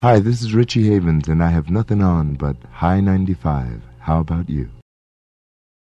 WQHI Richie Havens Liner